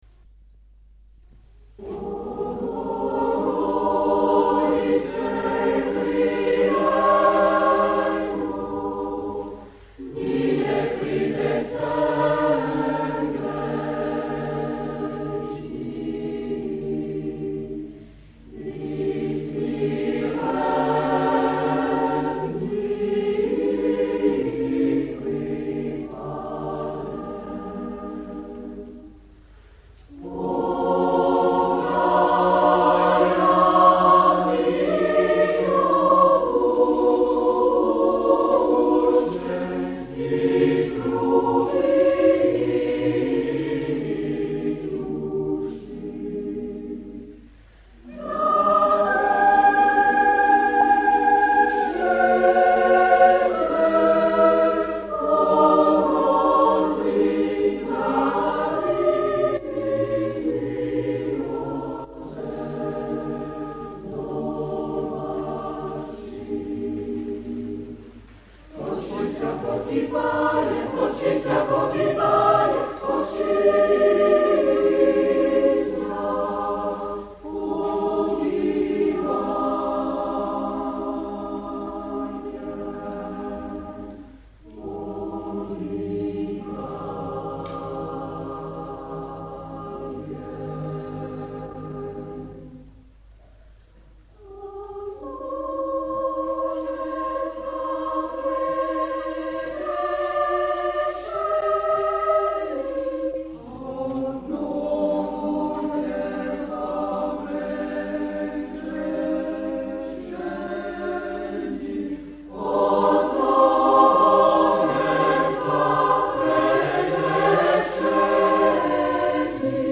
Eglise Notre Dame de la Paix MACON
Extraits du concert